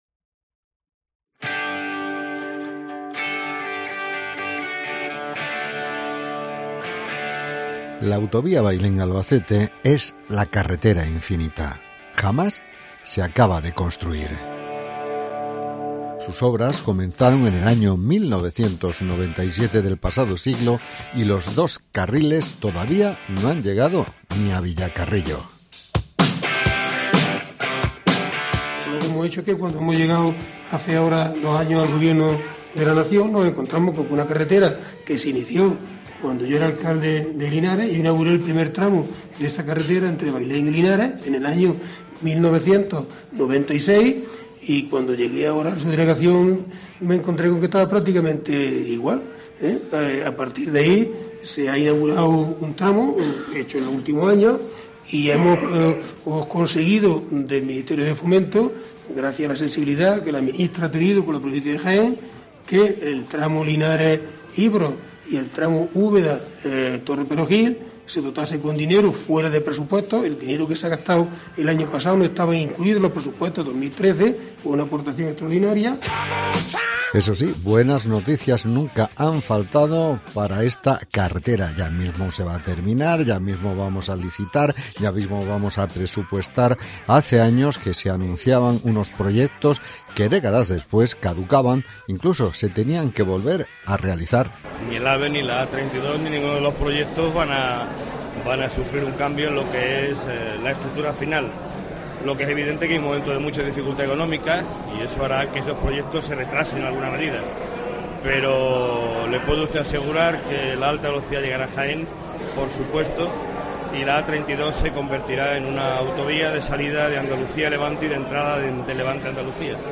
AUDIO: Tiramos de archivo en Jaén Decide para hacer memoria de las obras de esta autovía que comenzó a construir en la década de...
REPORTAJE